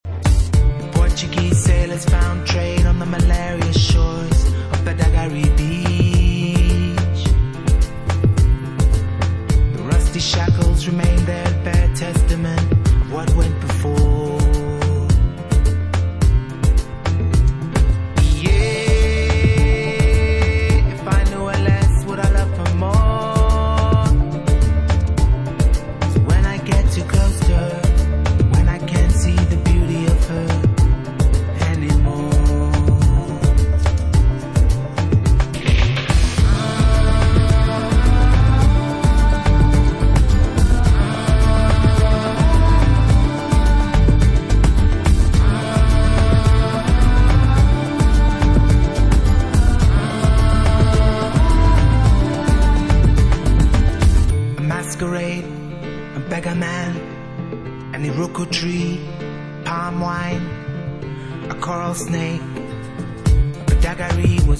SOULFUL HOUSE